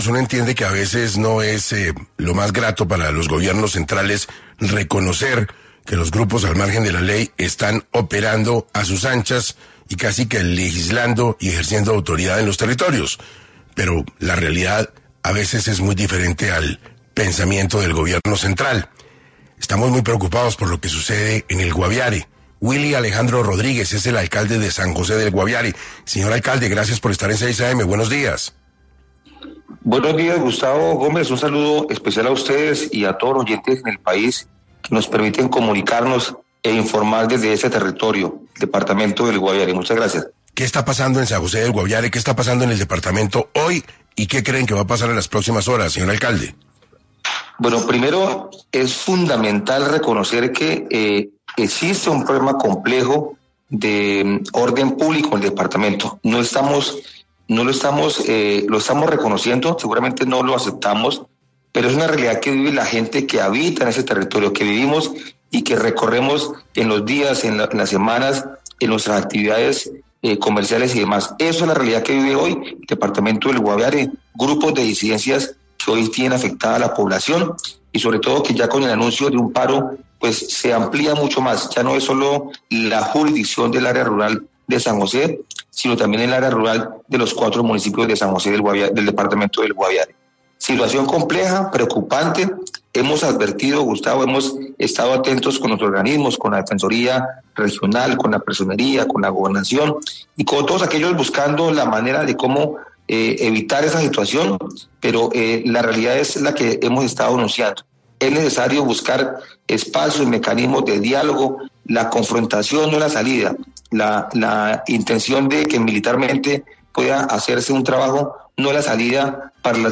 Willy Rodríguez, alcalde San José del Guaviare, habla del problema de orden público en este territorio del país.